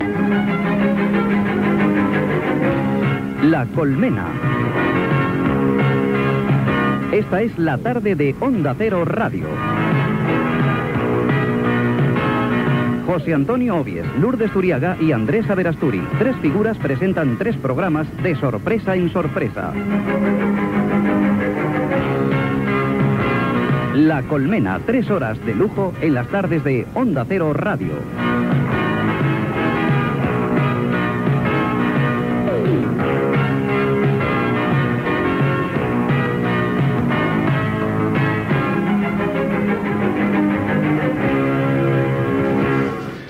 Careta del programa.